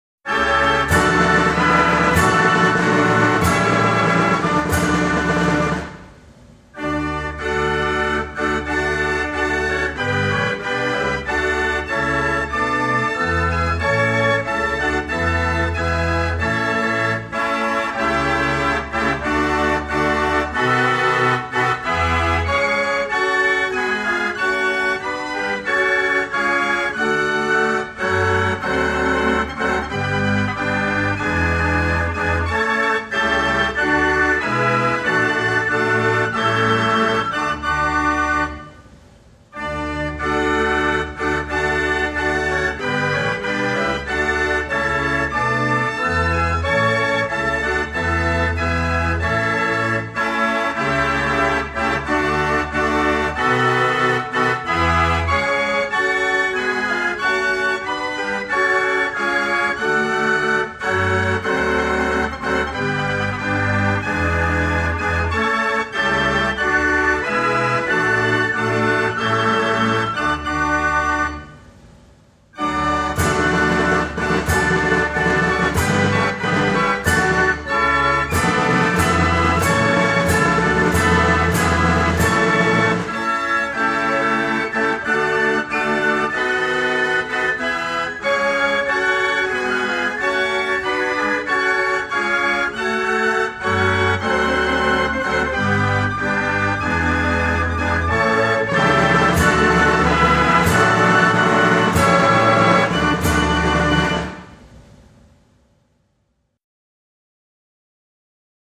Online radio met de mooiste draaiorgelmuziek